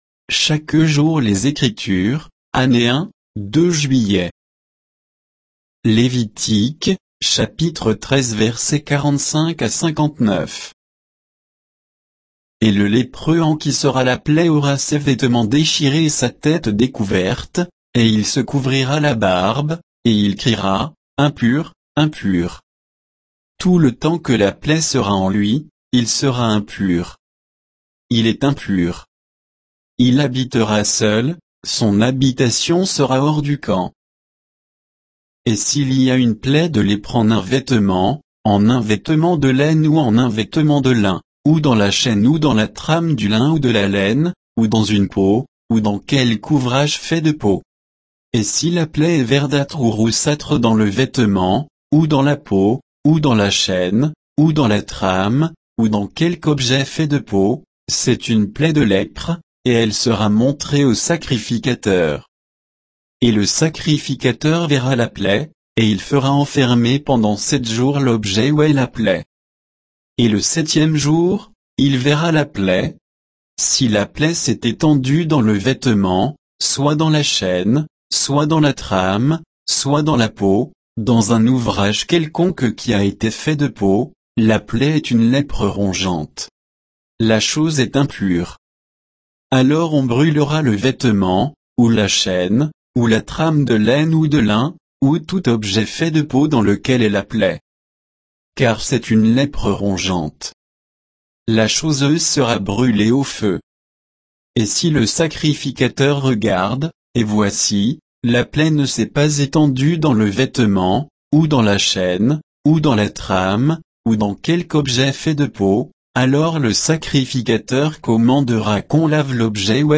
Méditation quoditienne de Chaque jour les Écritures sur Lévitique 13